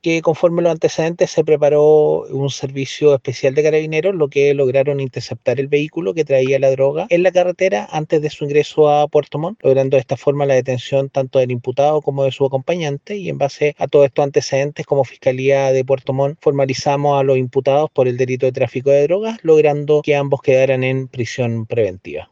En el operativo se detuvo a un hombre de nacionalidad colombiana de 42 años, el supuesto cabecilla de la organización, y a una mujer de 29 años, los que quedaron en prisión preventiva como detalló el Fiscal Jefe de Puerto Montt, Marcelo Maldonado.